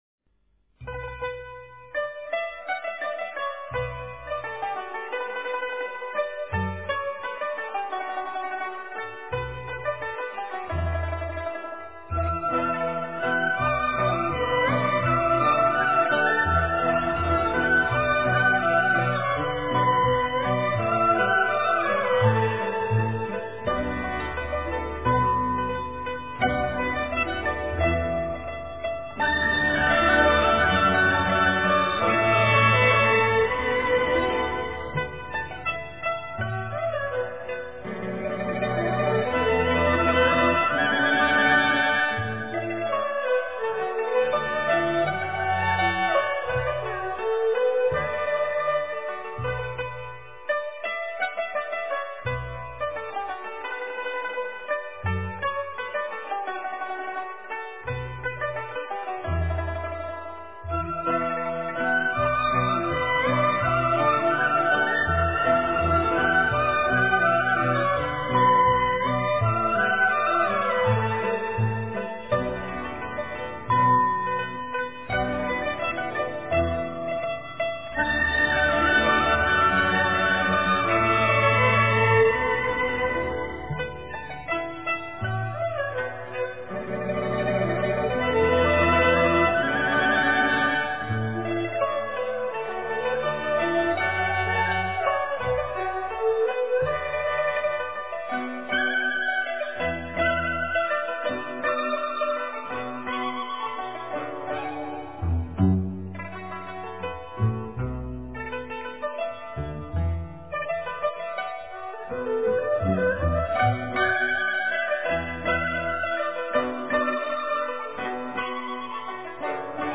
轻舒广袖--Pure Music 冥想 轻舒广袖--Pure Music 点我： 标签: 佛音 冥想 佛教音乐 返回列表 上一篇： You--钢琴曲 下一篇： 出水莲--古筝独奏 相关文章 楞严心咒--新韵传音 楞严心咒--新韵传音...